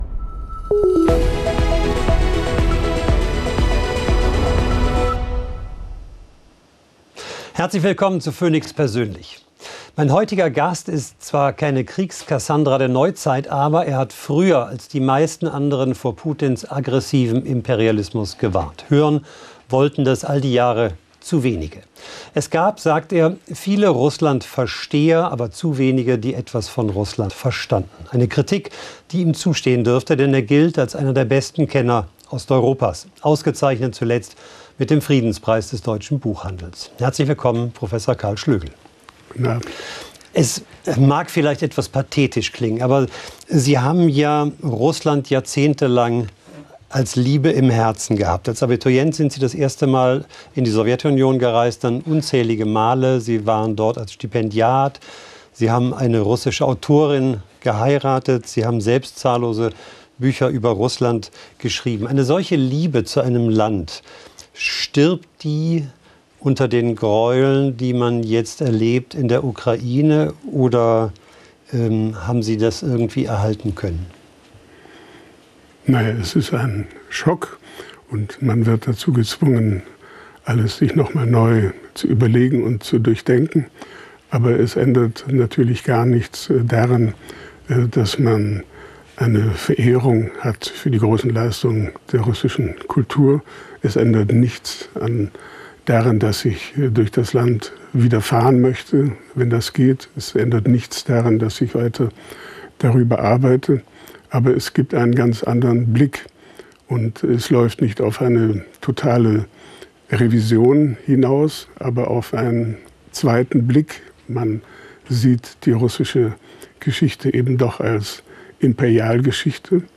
Historiker und Osteuropa-Experte Prof. Karl Schlögel zu Gast bei Theo Koll